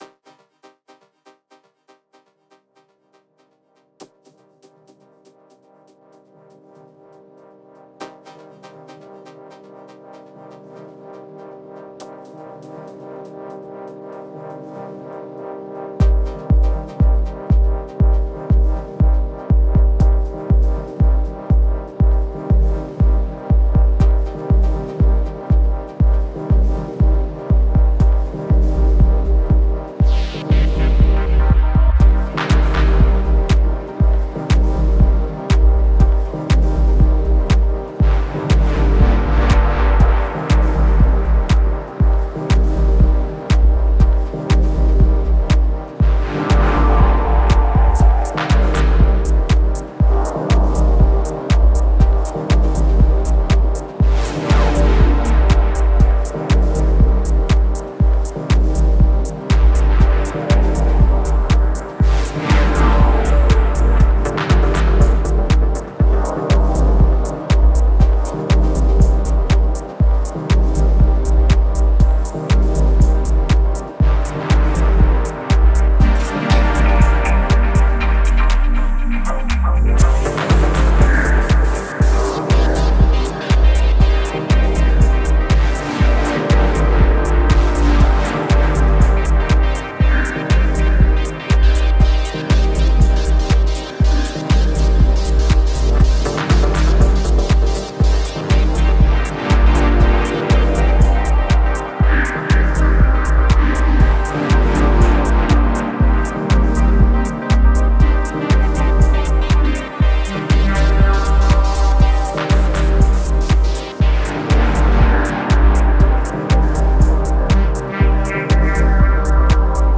Genre: Deep Techno/Ambient/Dub Techno.